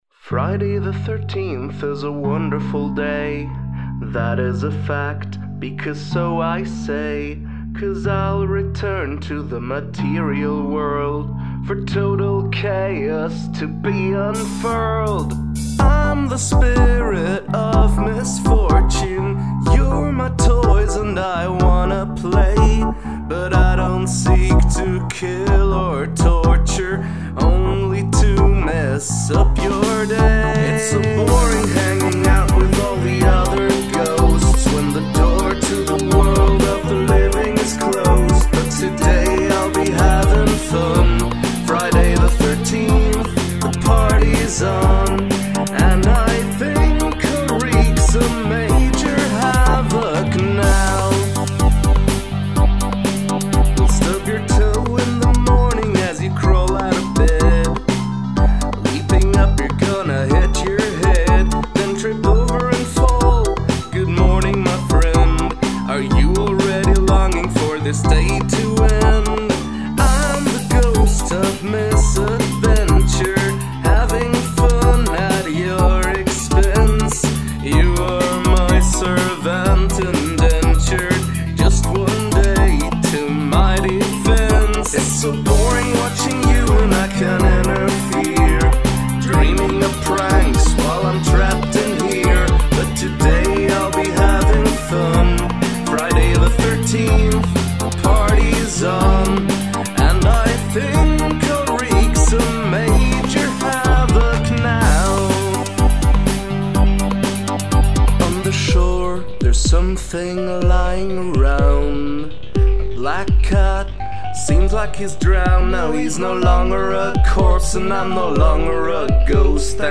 write a feel-good song with happy lyrics and upbeat music